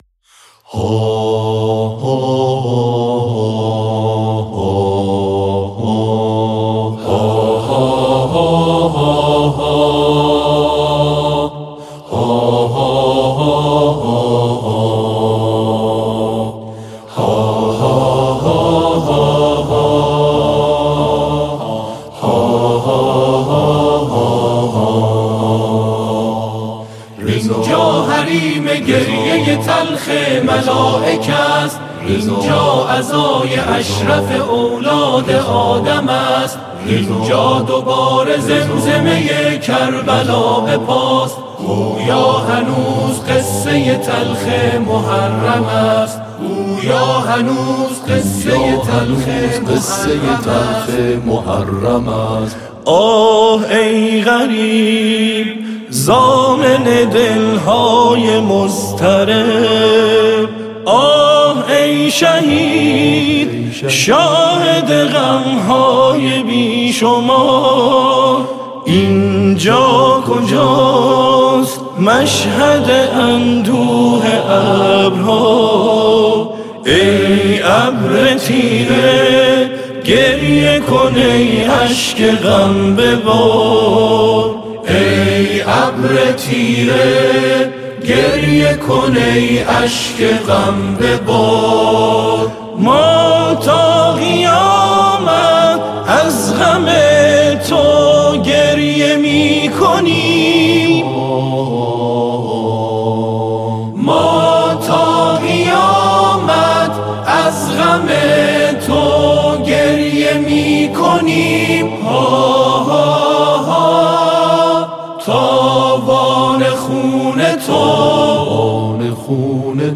براساس فرم موسیقی آکاپلا